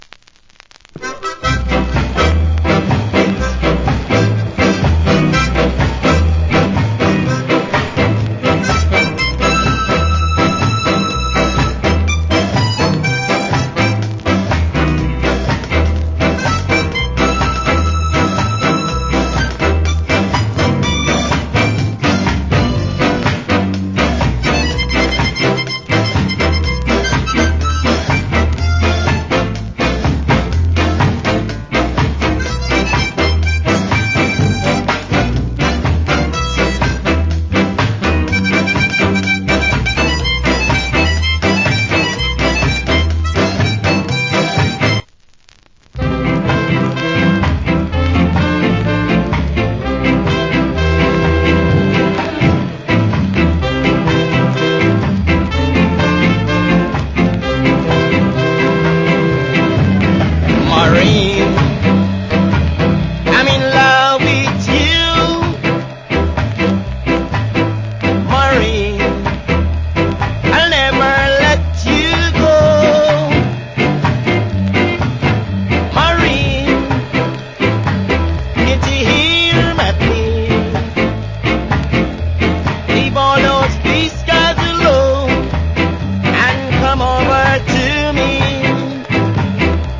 Nice Harmonica Ska Inst.